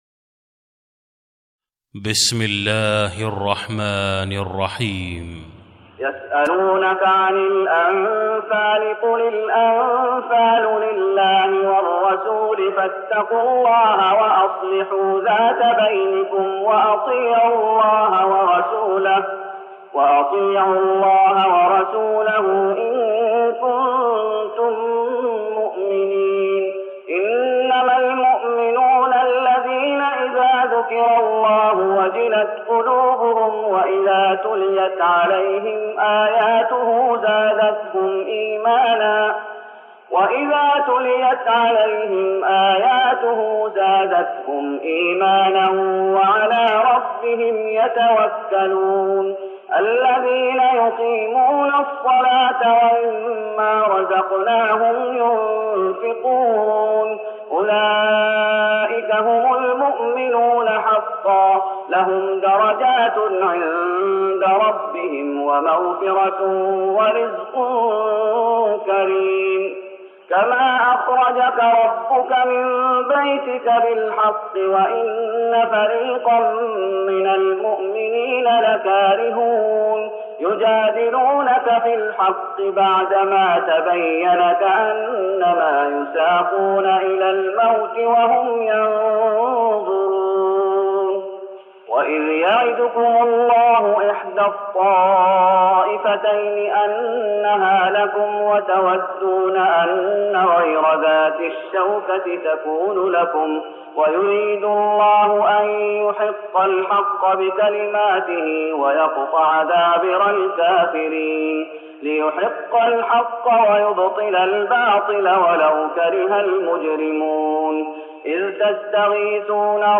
تراويح رمضان 1414هـ من سورة الأنفال (1-44) Taraweeh Ramadan 1414H from Surah Al-Anfal > تراويح الشيخ محمد أيوب بالنبوي 1414 🕌 > التراويح - تلاوات الحرمين